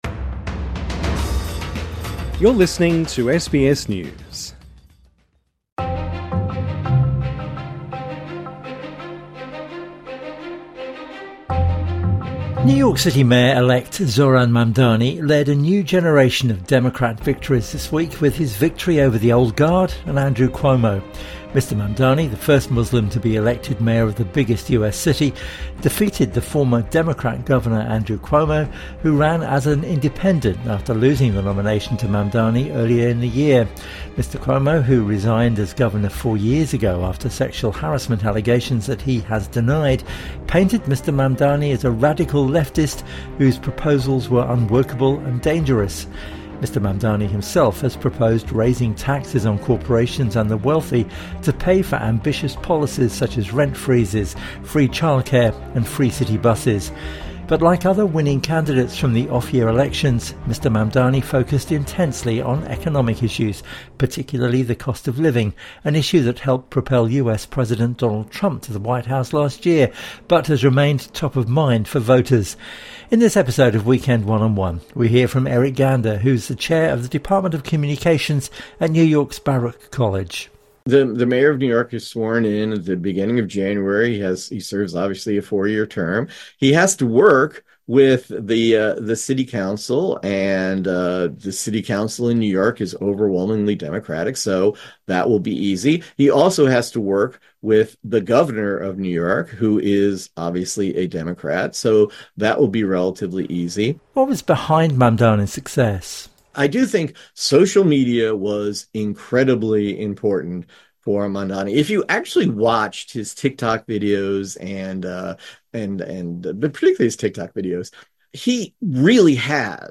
INTERVIEW: Can Zohran Mamdani deliver on his election promises?